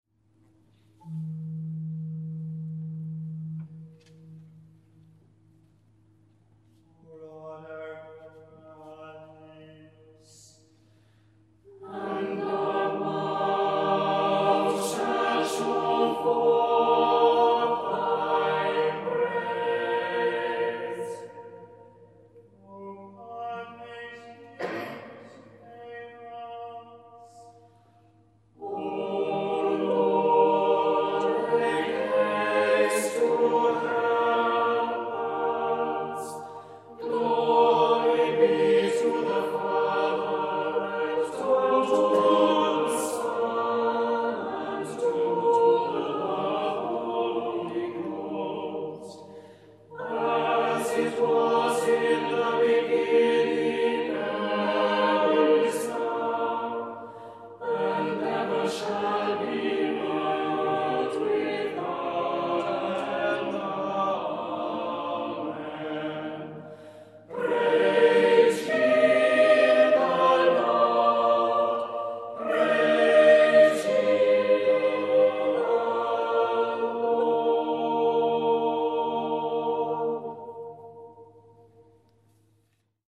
Byrd Preces, sung by the Priory Singers of Belfast at Truro Cathedral